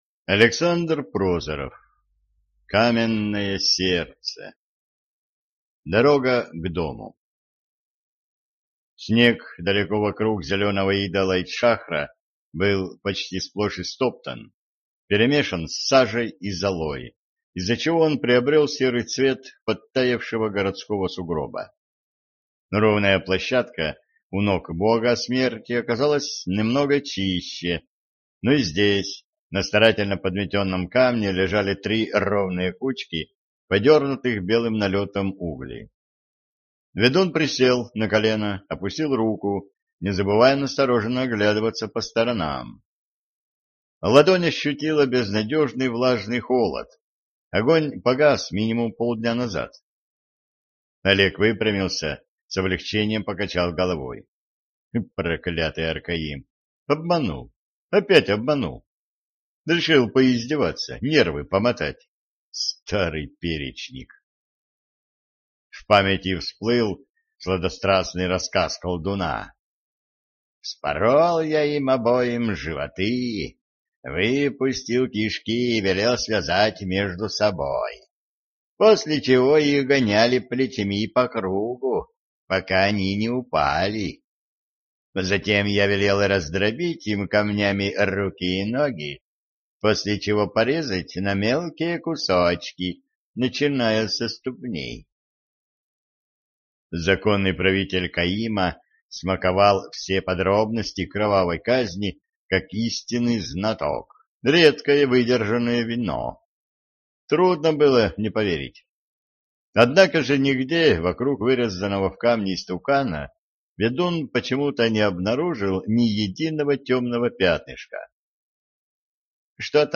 Аудиокнига Каменное сердце | Библиотека аудиокниг